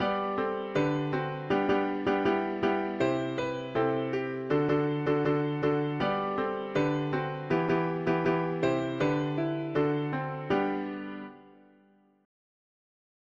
Music: Kenyan traditional Key: G major Meter: irregular
Tags swahili english theist 4part chords